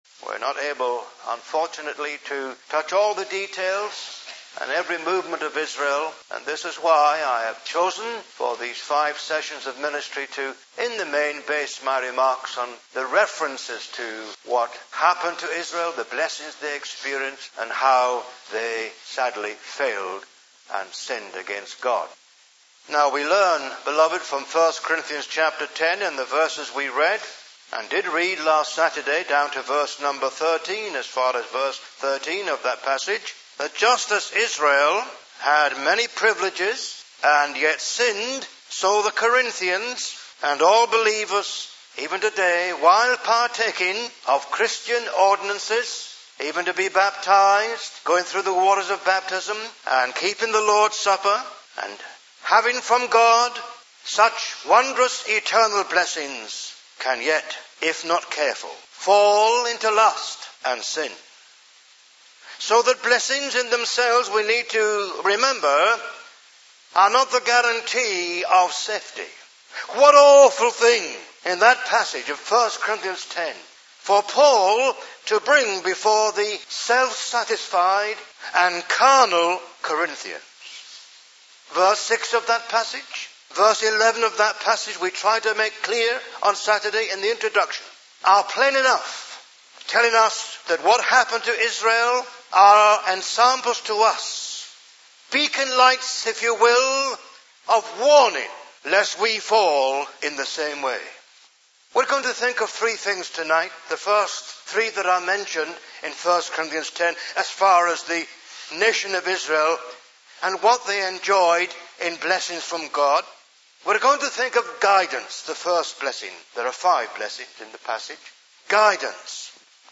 preaches on the incident of the crossing of the Red Sea by the children of Israel as they escaped from Egypt. He applies numerous pertinent lessons from this very instructive narrative (Message preached on 27th Nov 2006)